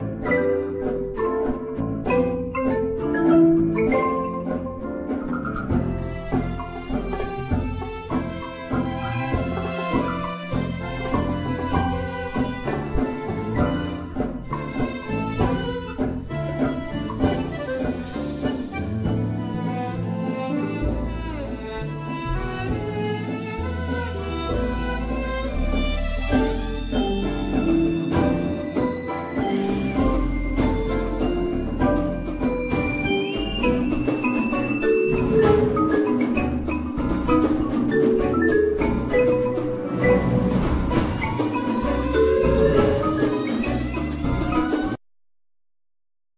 Accordeon,Vocals
Violin,Viola
Organ,Grand Piano,Pianet,Synthesizer,Vocals
Electric Basse,Bow,Darbuka,Guiro
Vibraphone,Marimba,Glockenspiel,Percussion
Drums,Bongos and Blocks